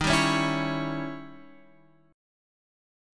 D#7b9 chord